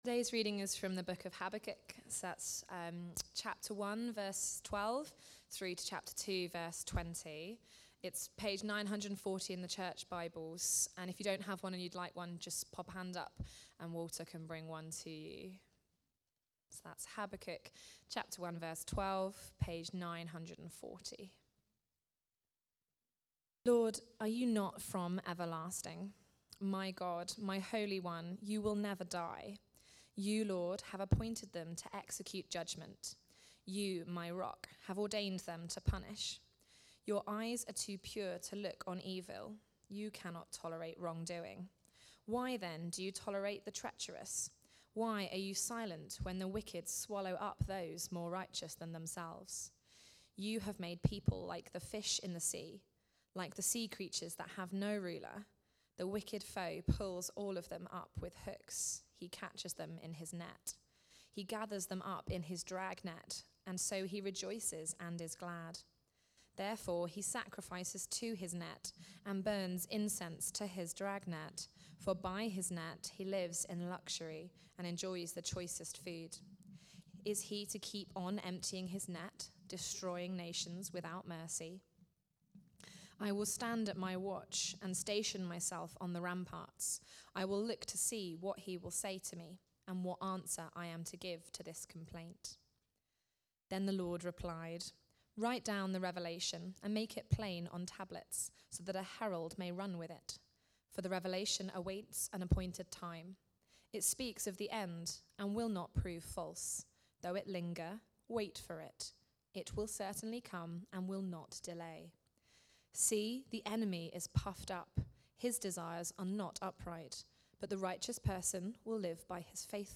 Say: Woe To The Wicked (Habakkuk 2:6-20) from the series Learning to Live By Faith. Recorded at Woodstock Road Baptist Church on 11 May 2025.